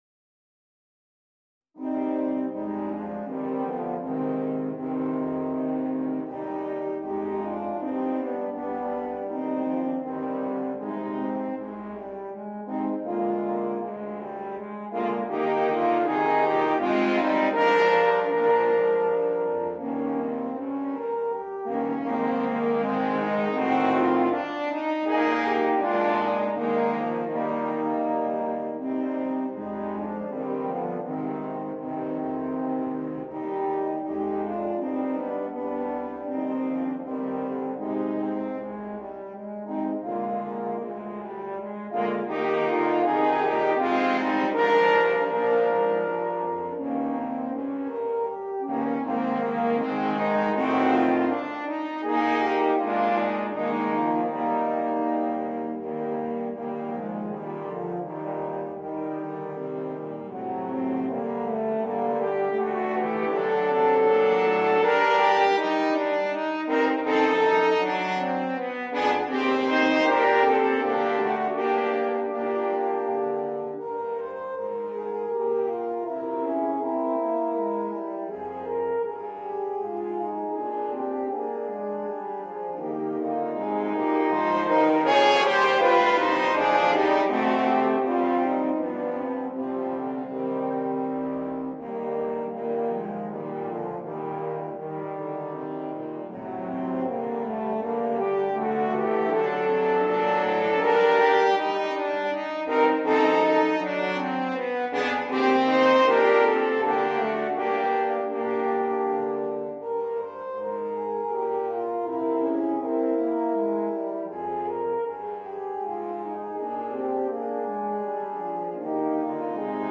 для квартета валторн.
• состав: Horn 1, Horn 2, Horn 3, Horn 4